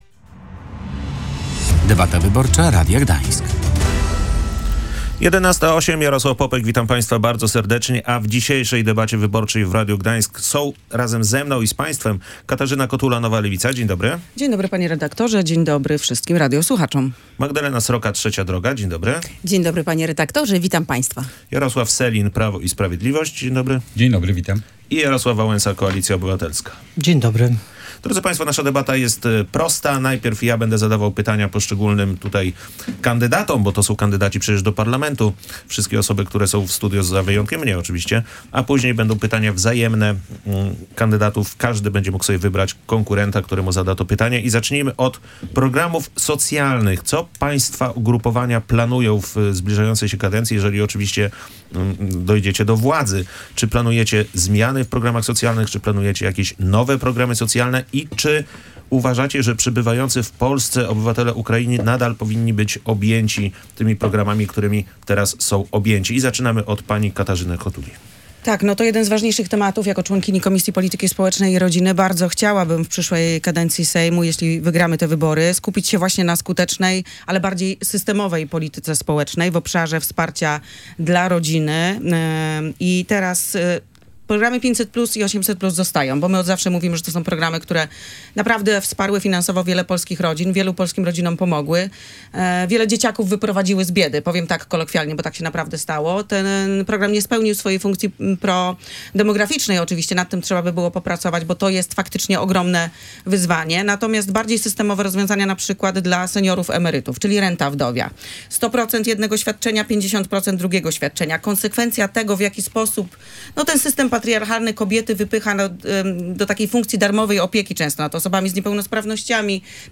Tradycyjnie już kandydaci odpowiadali na trzy pytania, każdy z nich dostał dokładnie taki sam zestaw pytań. I każdy z nich miał dokładnie tyle samo czasu na odpowiedź.